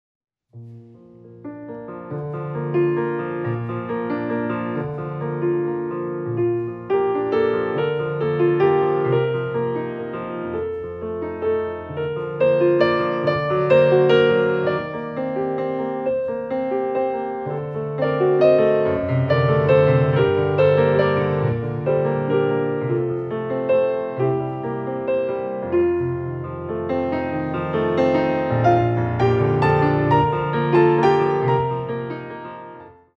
Ballet class music for Intermedite Level
Beautifully recorded on a Steinway and Sons Grand Piano
8x8 + 1x12 6/8